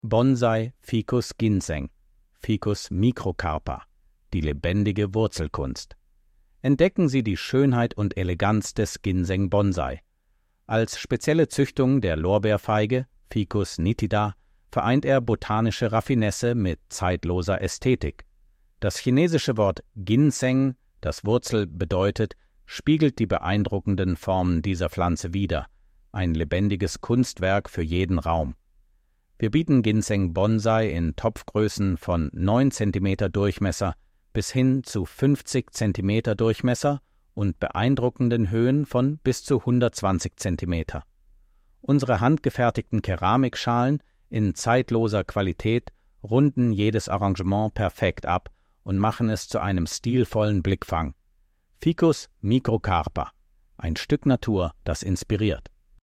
Audio-Datei mit vorgelesenem Text über die Gärtnerei Hohn.